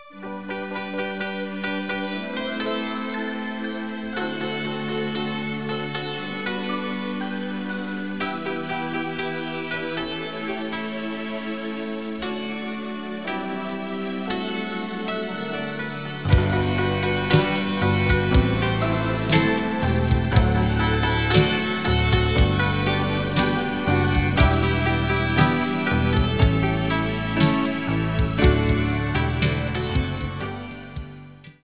electric guitar
electric organ
drums